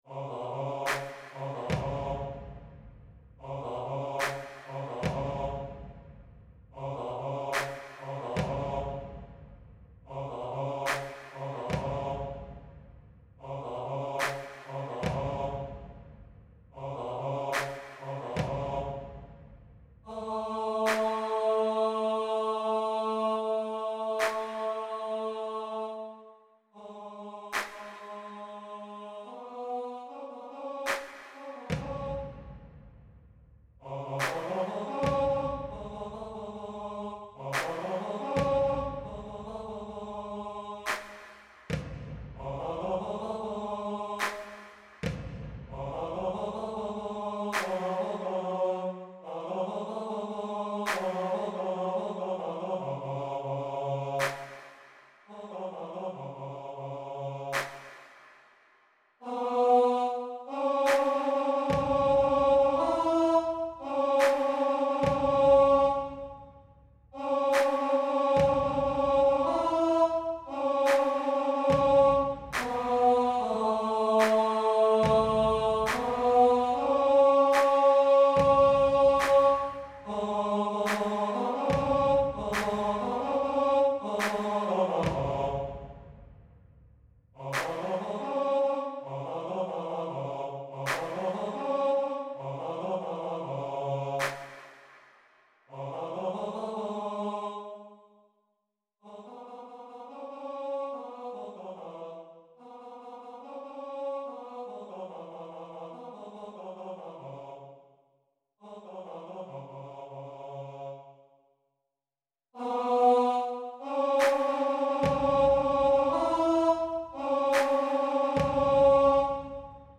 Human-Tenor.mp3